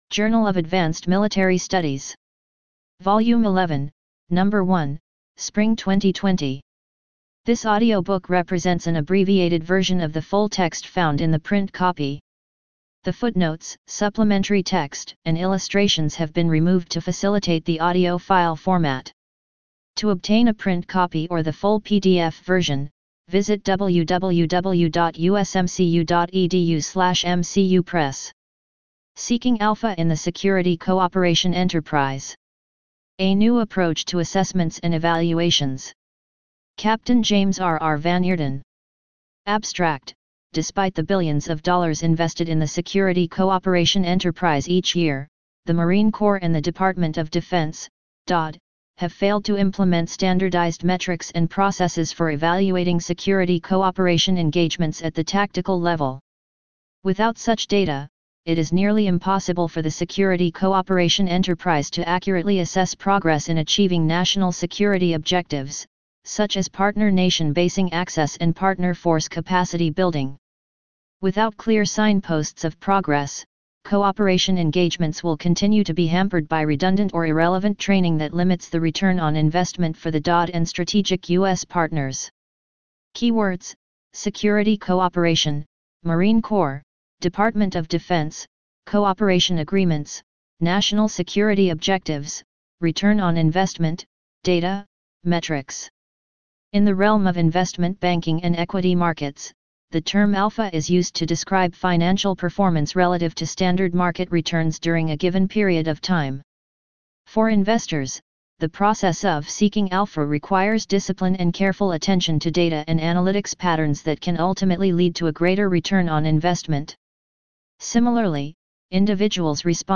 JAMS_Seeking Alpha_audiobook.mp3